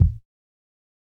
BattleCatKick5.wav